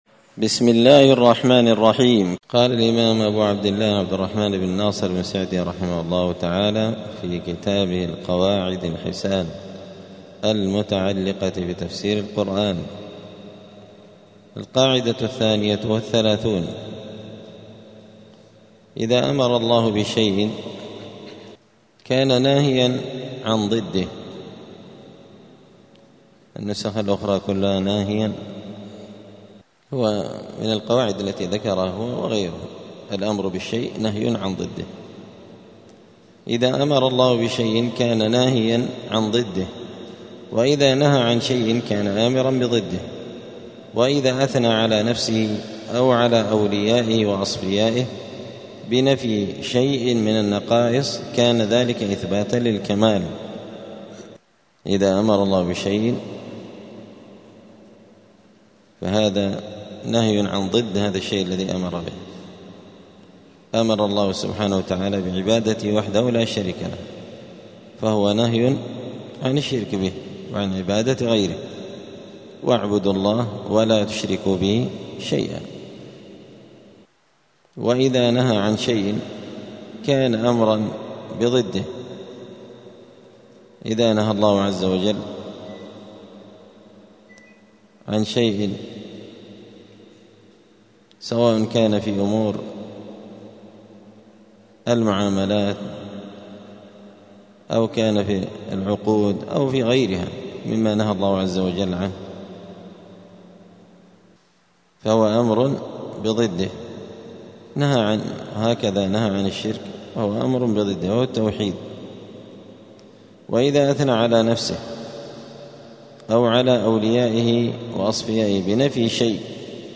دار الحديث السلفية بمسجد الفرقان قشن المهرة اليمن
43الدرس-الثالث-والأربعون-من-كتاب-القواعد-الحسان.mp3